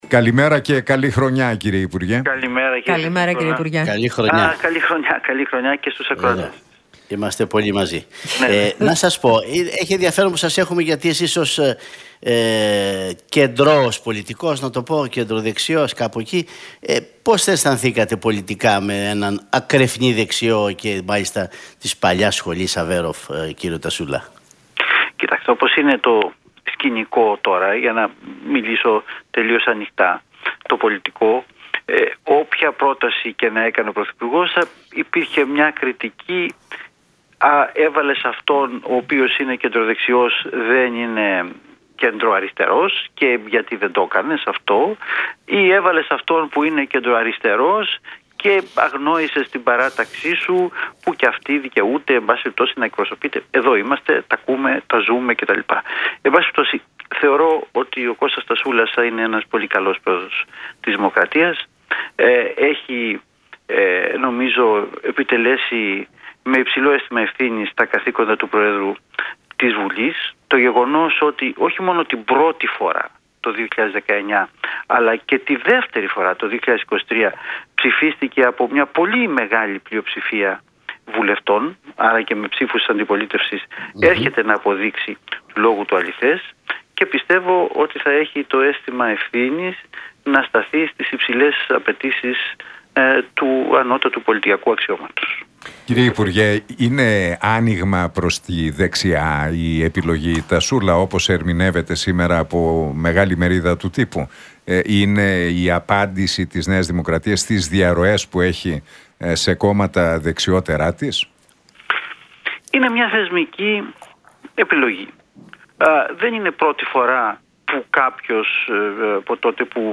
Για την εκλογή προέδρου της Δημοκρατίας, το εσωκομματικό κλίμα στην ΝΔ, την ακρίβεια και τους φόρους μίλησε ο υπουργός Εθνικής Οικονομίας και Οικονομικών,